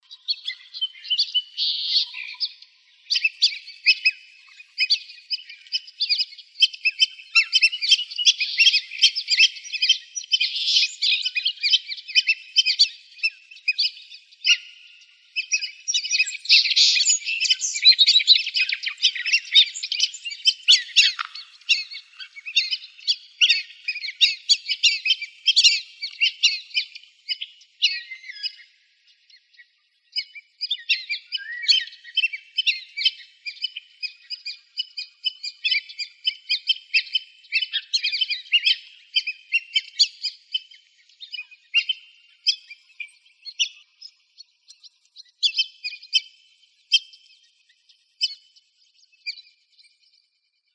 whistlingduck.wav